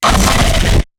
Player_UI [139].wav